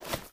STEPS Dirt, Run 22.wav